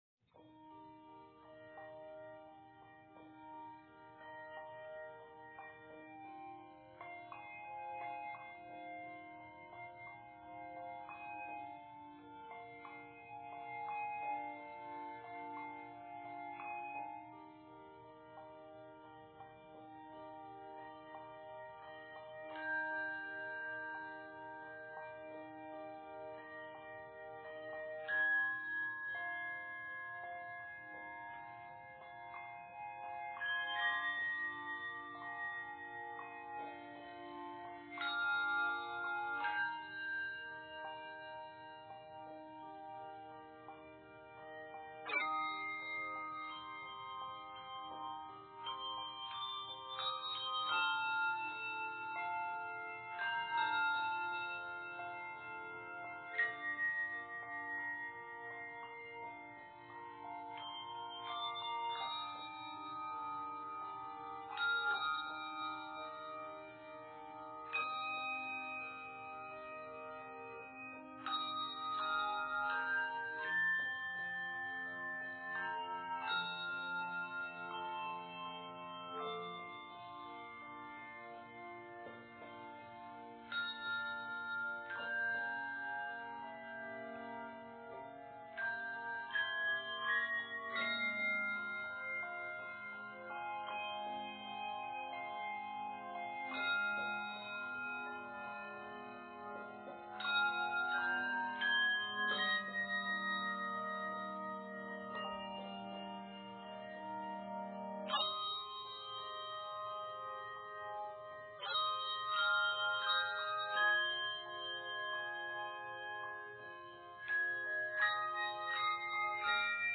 3 to 5 octaves of handbells or chimes
arranged in Eb Major and is 81 measures
arranged in F Major and is 65 measures